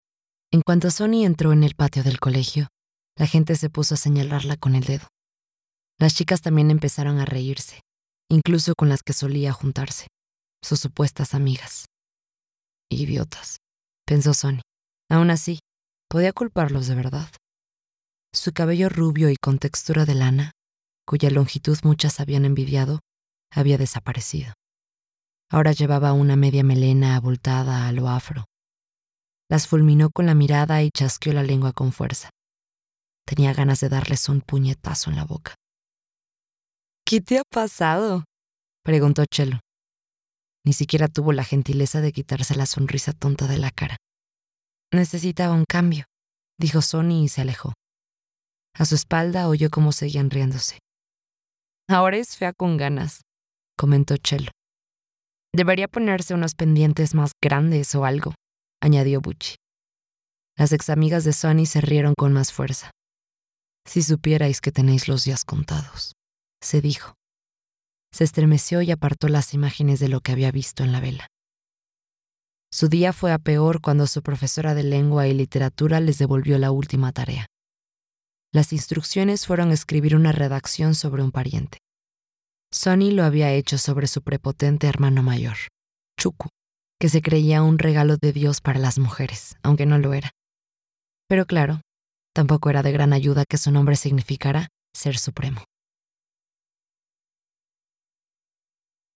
Audiolibro Bruja Akata (Akata Witch)